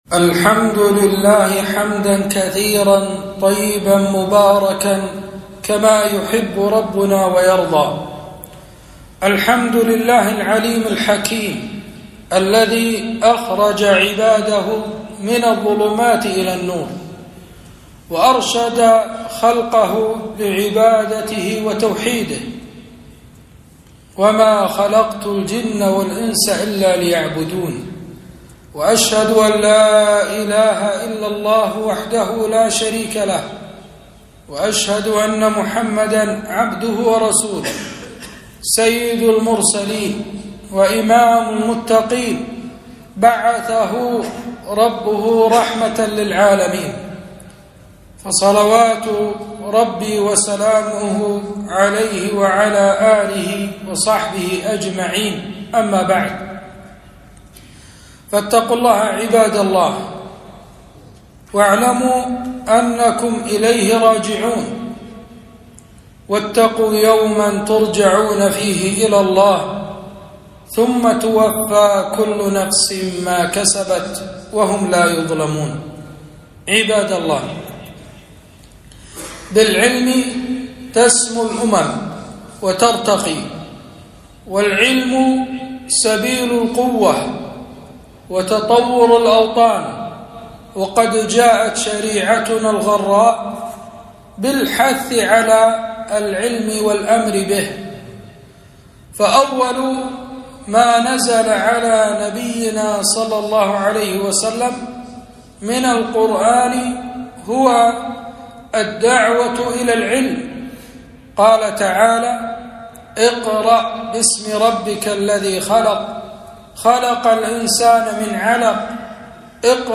خطبة - فضل العلم وشرف أهله